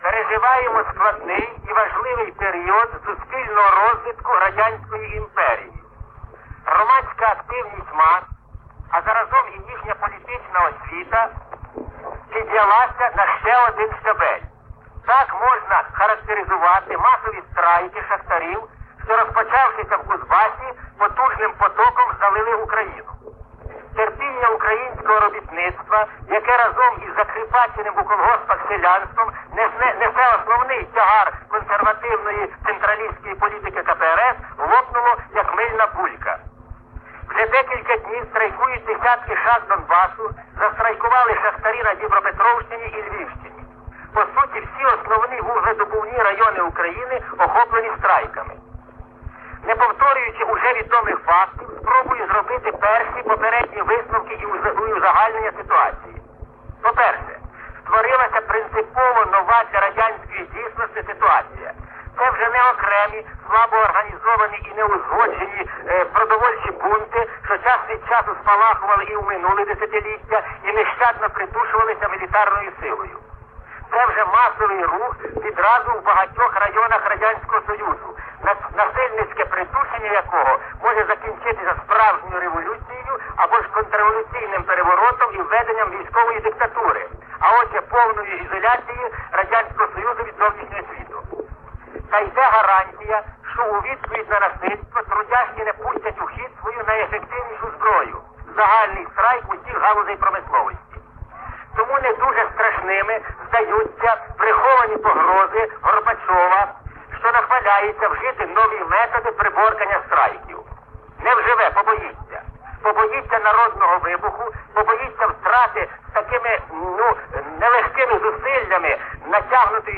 Із архіву Радіо Свобода (1989 рік): заява Чорновола щодо страйку шахтарів